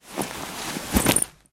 Звуки портфеля